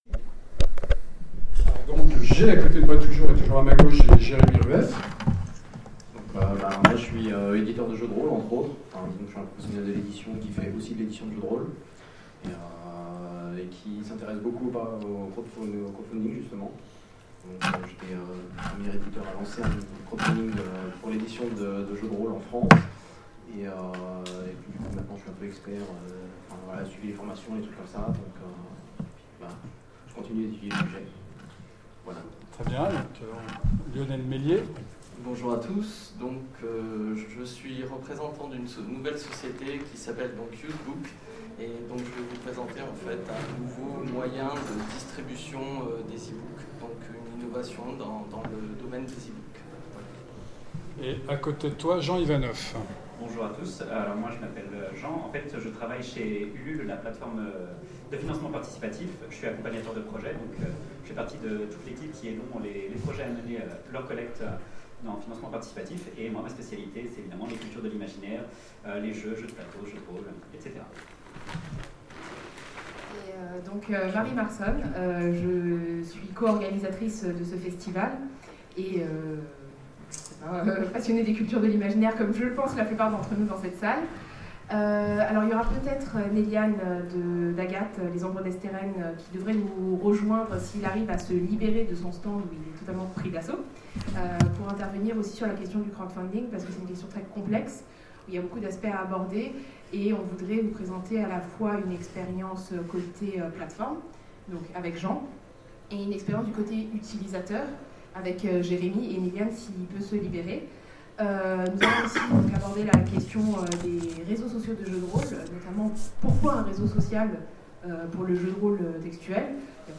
FMI 2016 : Conférence Innovation